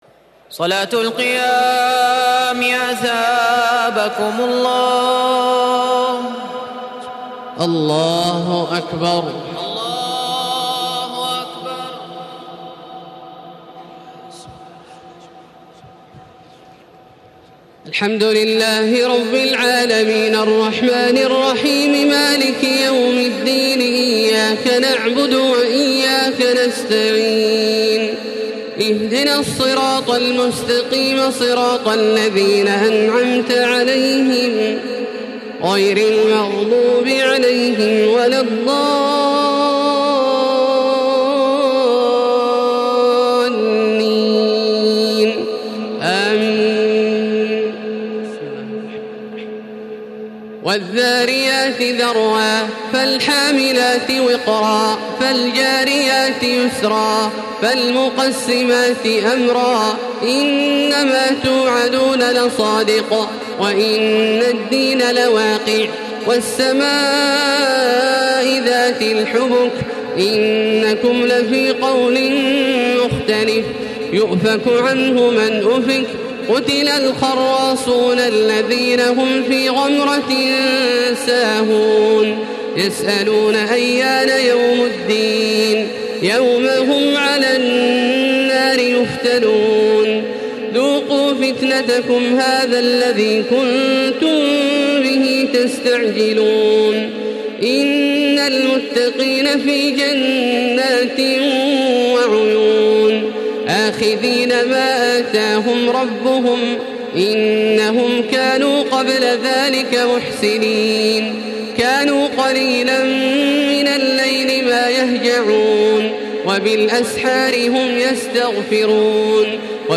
تراويح ليلة 26 رمضان 1435هـ من سور الذاريات و الطور و النجم و القمر Taraweeh 26 st night Ramadan 1435H from Surah Adh-Dhaariyat and At-Tur and An-Najm and Al-Qamar > تراويح الحرم المكي عام 1435 🕋 > التراويح - تلاوات الحرمين